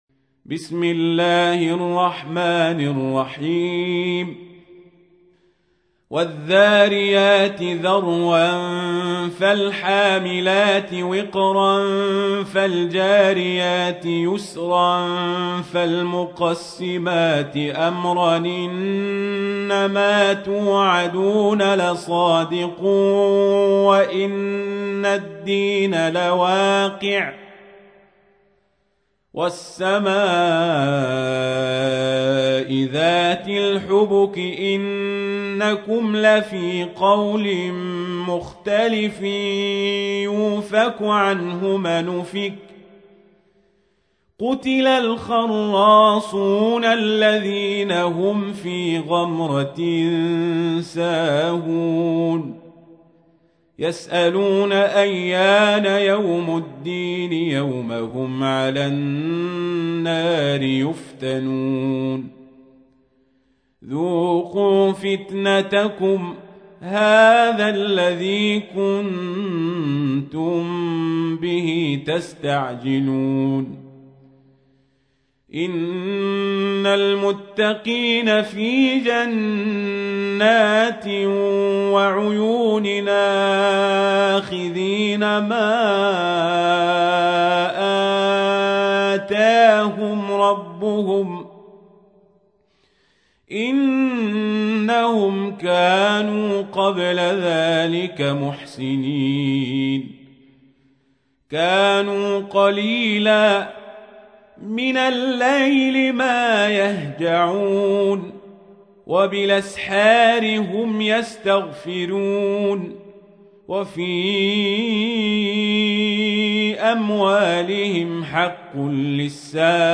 تحميل : 51. سورة الذاريات / القارئ القزابري / القرآن الكريم / موقع يا حسين